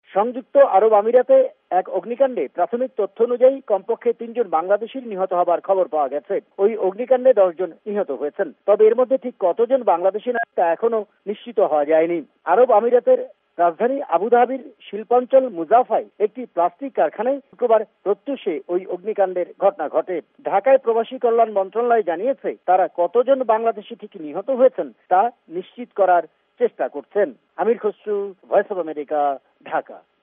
ঢাকা সংবাদদাতাদের রিপোর্ট